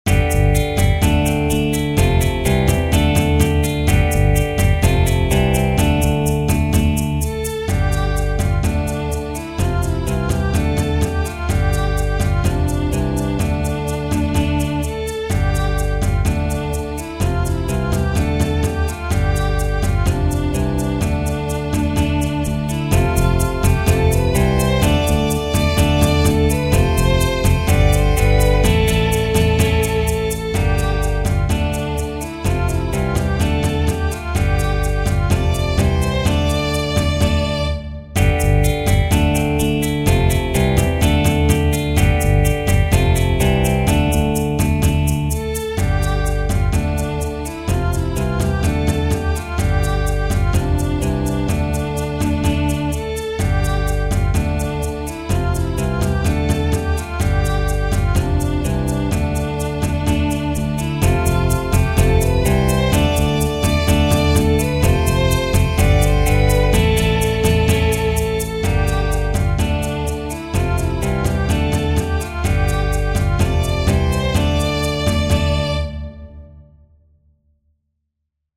Tradizionale Genere: Folk Lyrics by anonymous There was three men came out of the west, Their fortunes for to try, And these three men made a solemn vow, John Barleycorn should die.